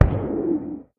守卫者：受伤
守卫者在水中受伤
Minecraft_guardian_guardian_hit4.mp3